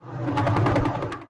scpcb-godot/SFX/Ambient/Zone1/Ambient3.ogg at 34e20b9e84c4340c5663a408ff2cef388eb0407a
Ambient3.ogg